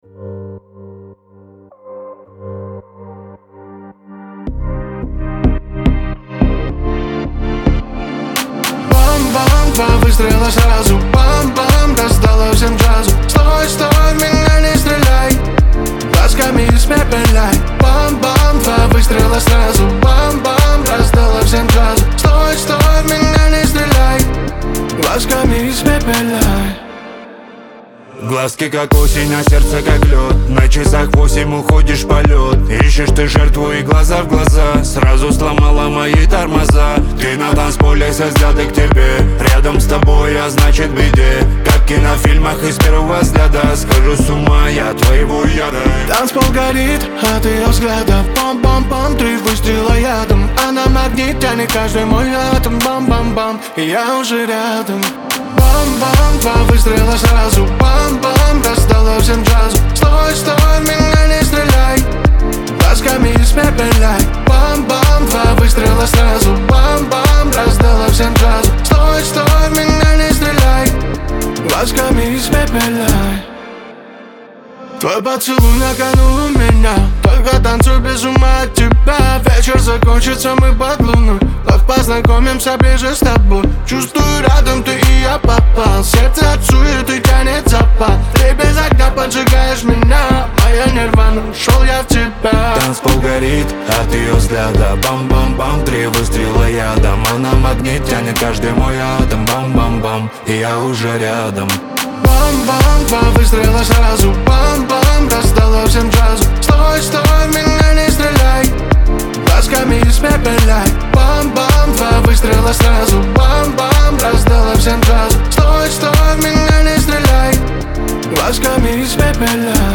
Кавказ – поп
танцевальная музыка
дуэт